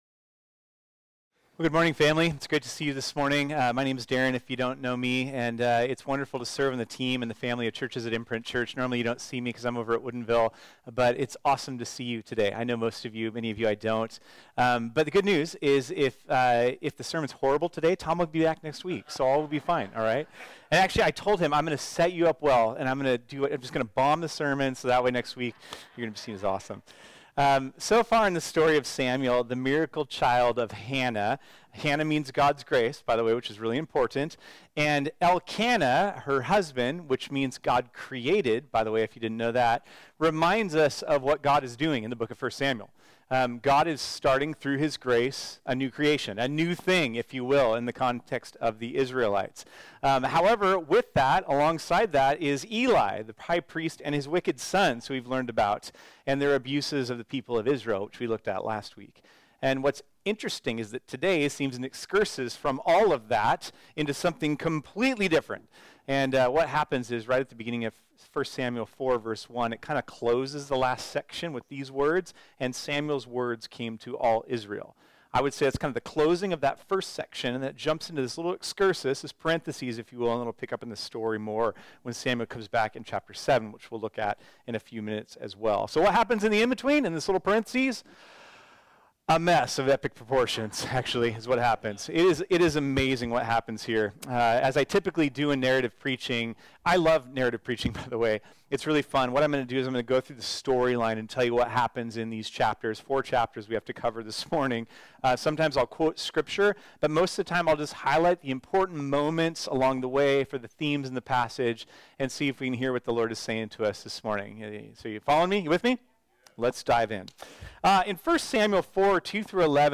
This sermon was originally preached on Sunday, April 30, 2023.